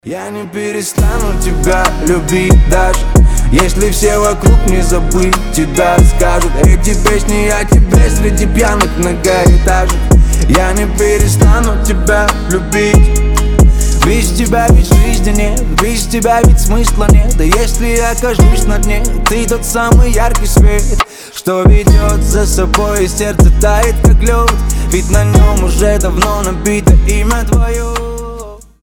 • Качество: 320, Stereo
лирика
душевные